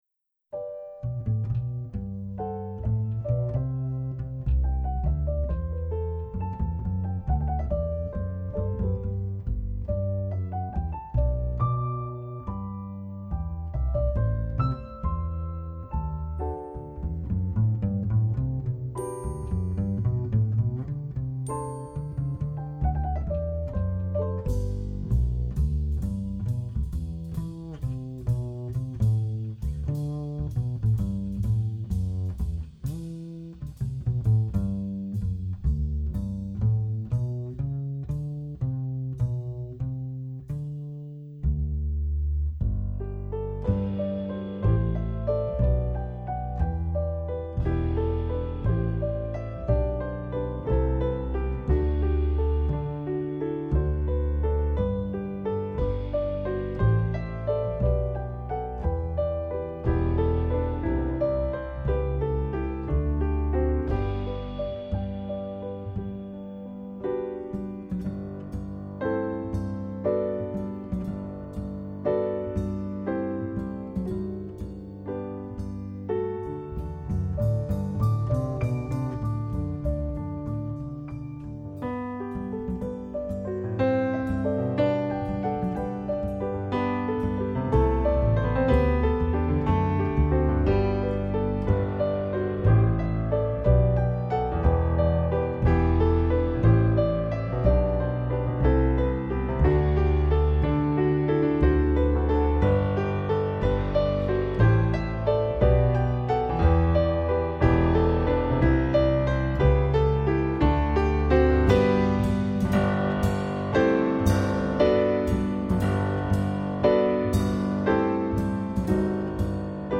-   爵士及藍調 (296)